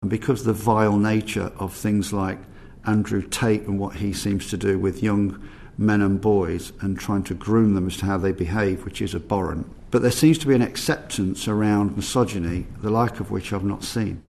Chief Constable of the PSNI, Jon Boutcher says worrying trends are emerging: